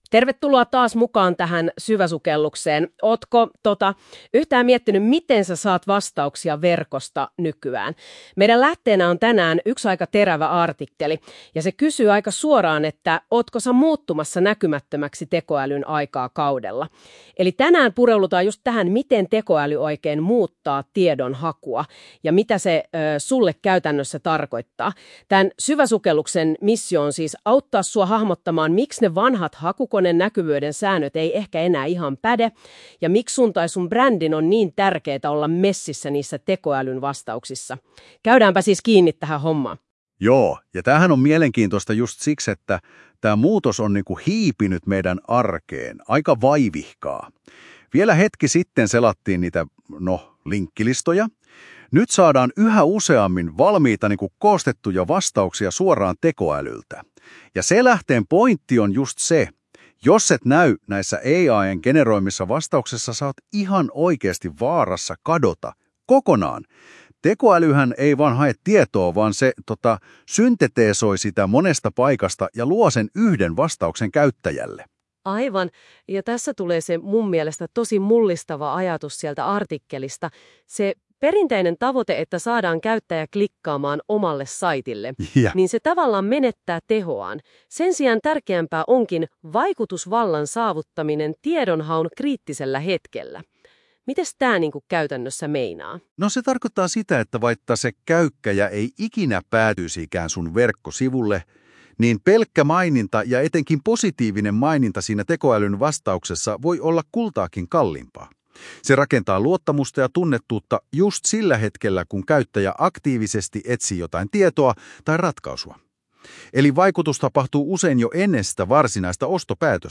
Syvenny aiheeseen kuuntelemalla artikkeli podcast tyylisesti keskusteluna tekoälyhaun vaikutuksista ja brändin näkyvyydestä.